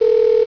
DingDong.wav